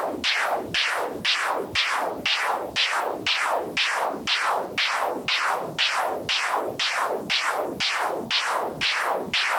STK_MovingNoiseF-100_01.wav